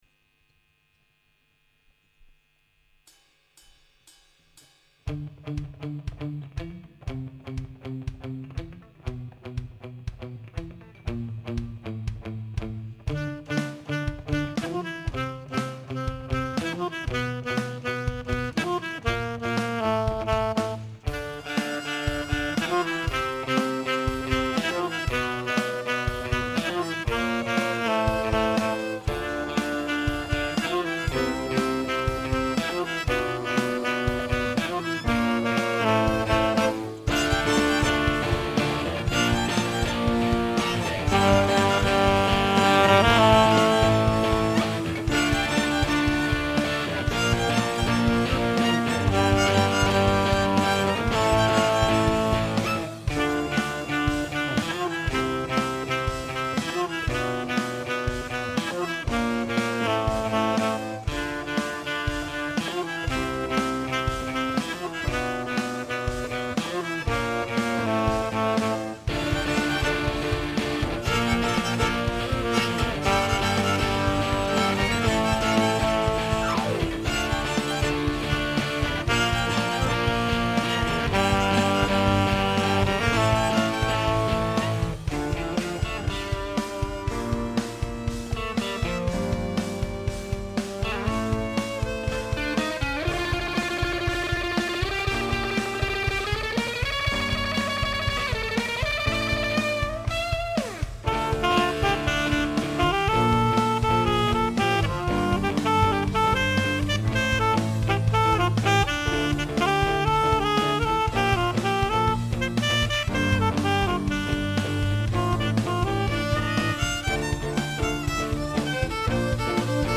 Mardi 6 juin, le Théâtre de Privas nous faisait l'honneur d'ouvrir sa scène aux élèves d'options et de spécialités artistiques du lycée.
Un programme dense, riche et de qualité où se sont succédés prestations musicales, théâtrales et dansées, devant un public conquis.
Une soirée mémorable dont vous trouverez en pièces jointes les fichiers audio des trois morceaux joués et chantés par les élèves musiciens.